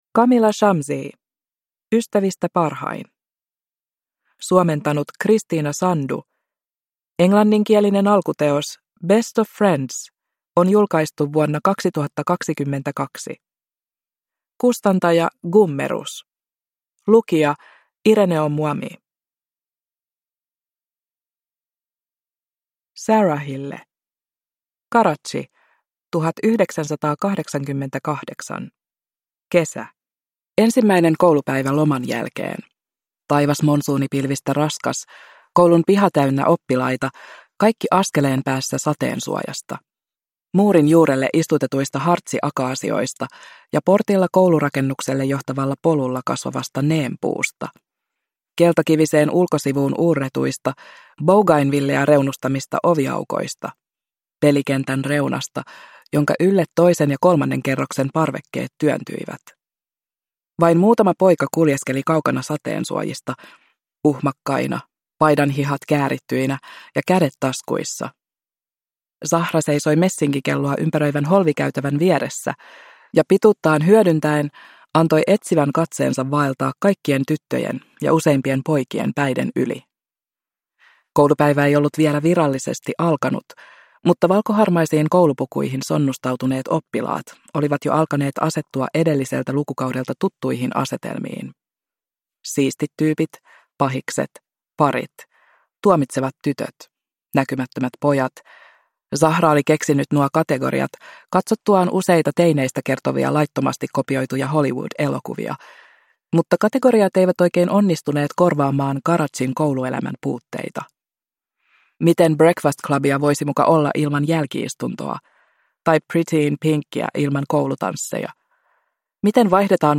Ystävistä parhain – Ljudbok